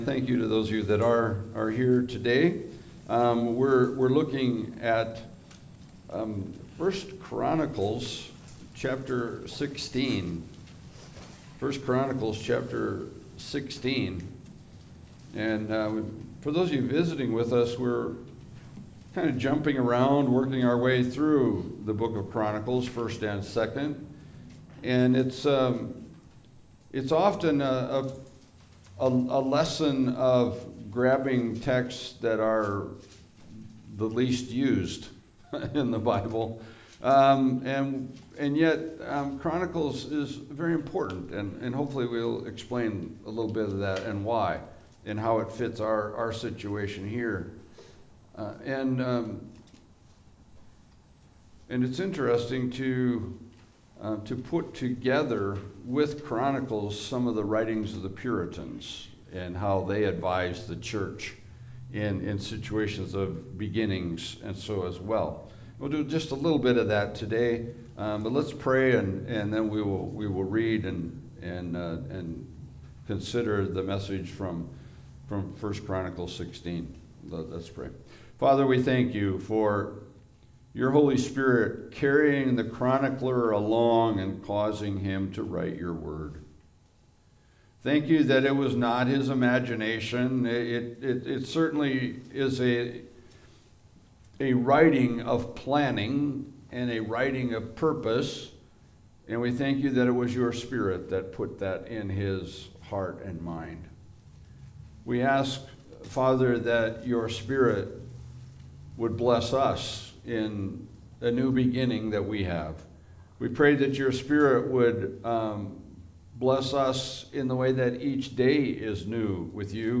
1 Chronicles 16 Service Type: Sunday Service « When God Rains on Our Parade There Is None Like You